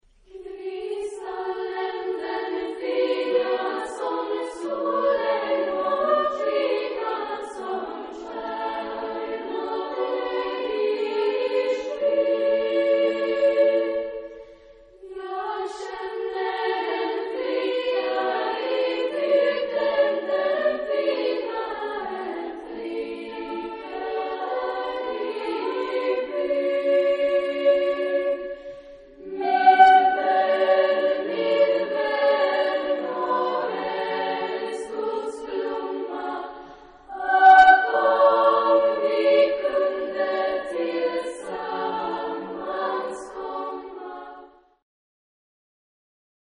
Genre-Style-Form: Partsong ; Folk music ; Secular
Type of Choir: SSAA OR TTBB  (4 equal voices )
Tonality: F sharp minor
Girls Choir